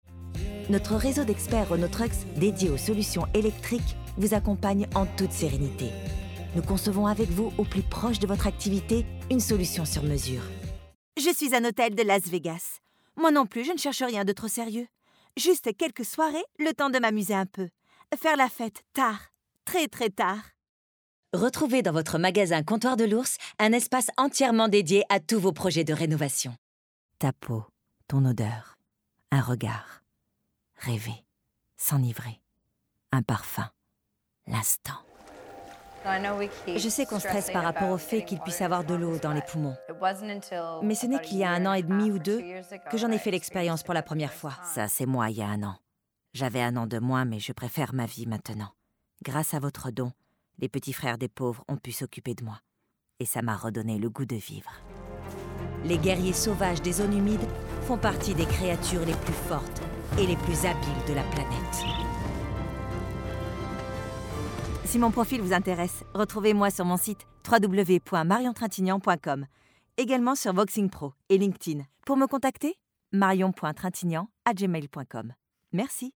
Voix off
Bandes-son
Voix Documentaire-Pub
8 - 85 ans - Mezzo-soprano